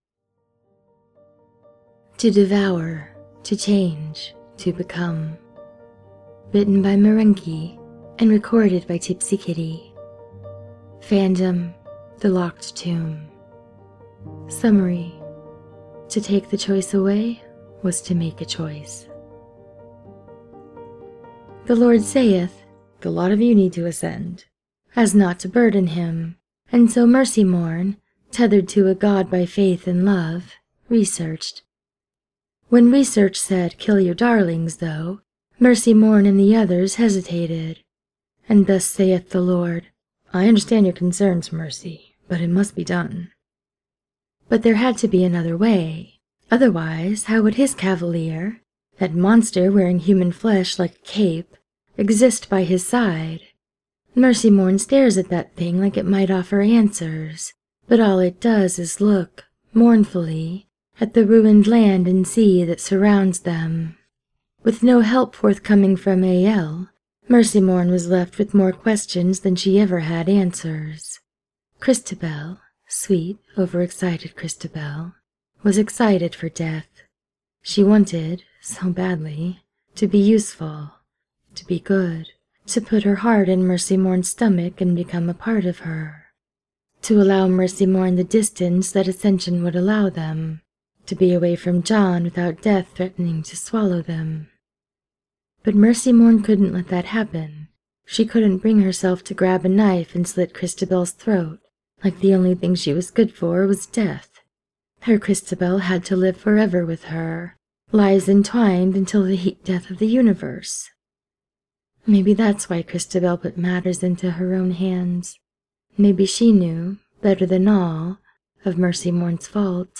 with music: